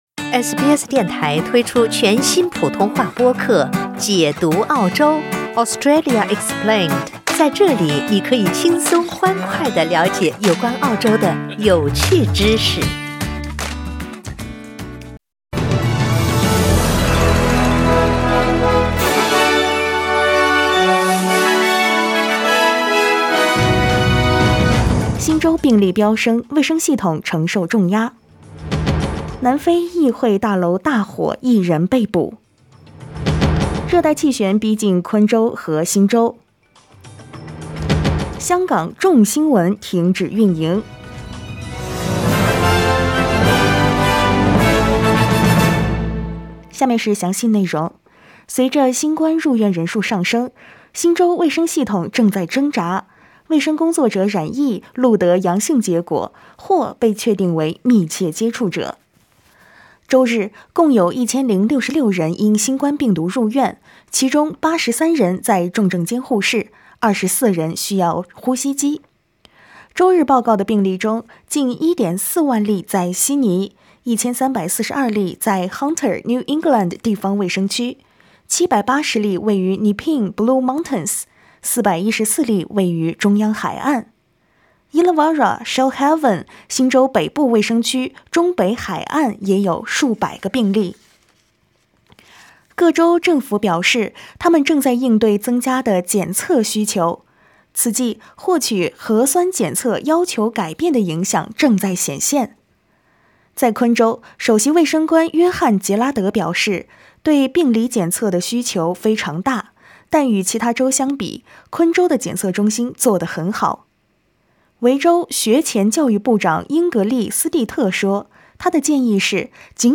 SBS早新闻（1月3日）
SBS Mandarin morning news Source: Getty Images